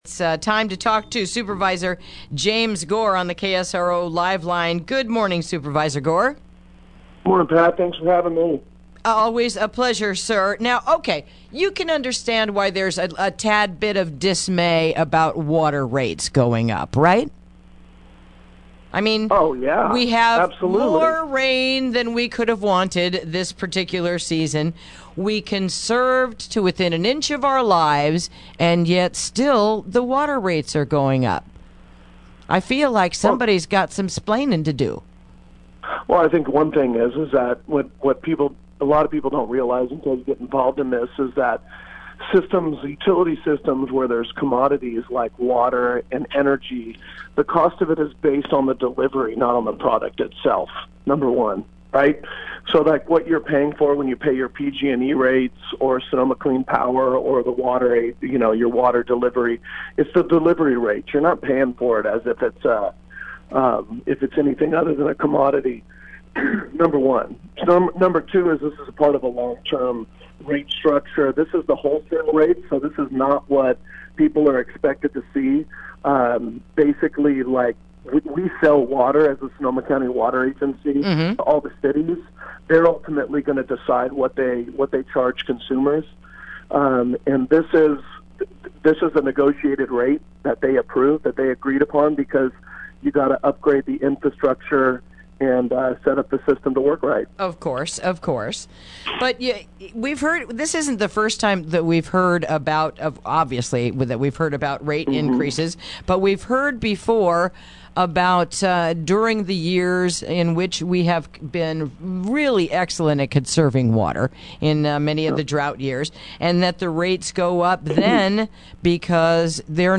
Interview: Water Rates Going Up